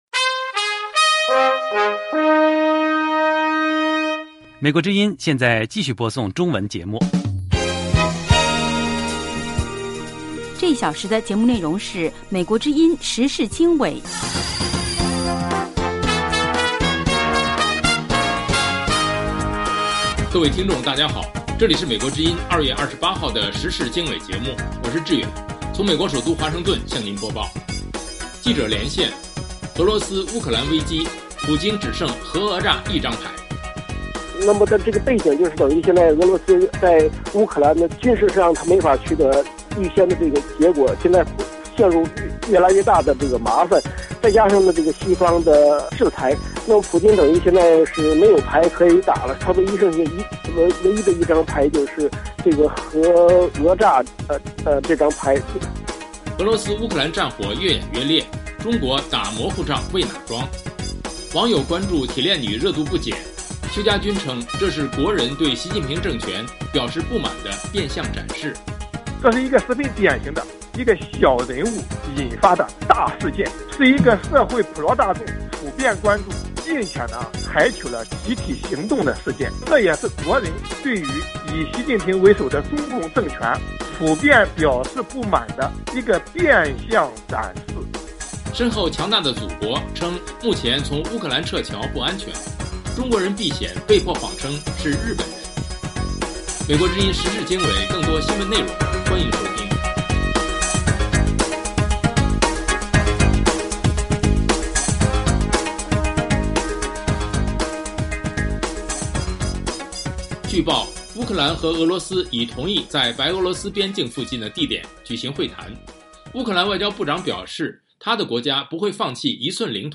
时事经纬(2022年2月28日)：1/记者连线：俄乌危机，普京只剩核讹诈一张牌。